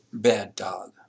Examples with Downsteps
bad-dog-disappointed.wav